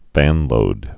(vănlōd)